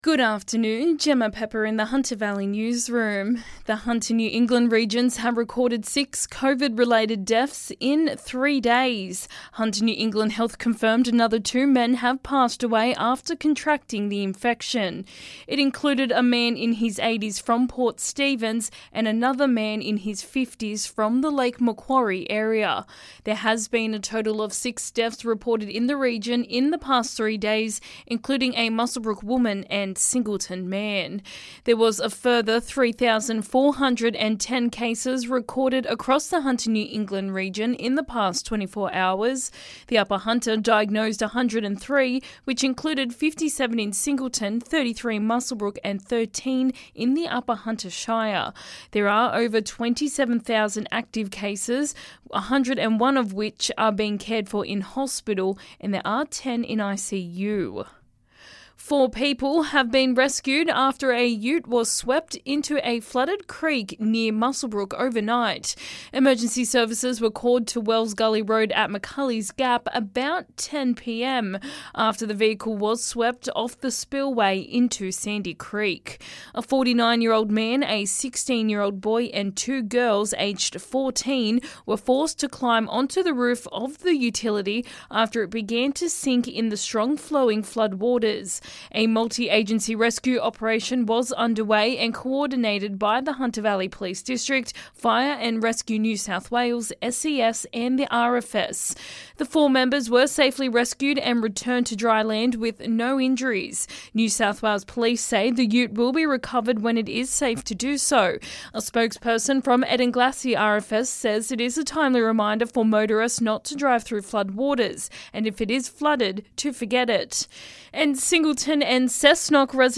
LISTEN: Hunter Valley Local News Headlines 12/01/2022